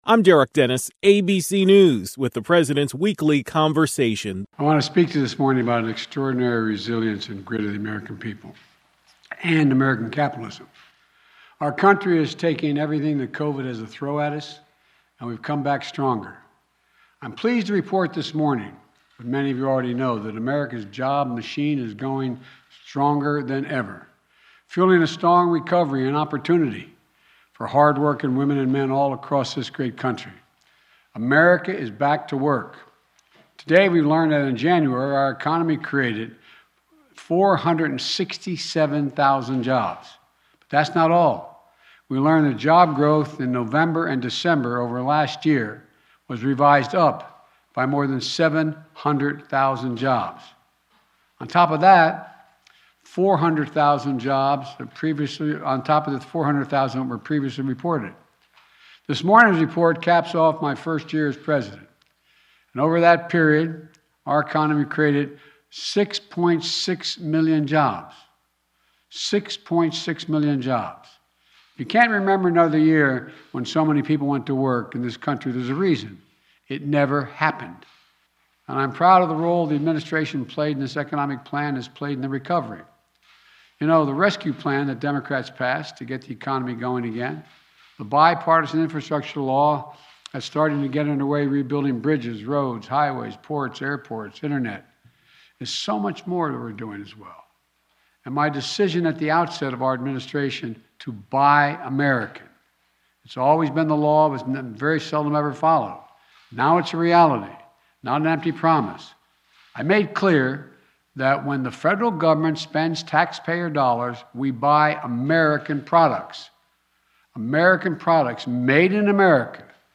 Here are his words: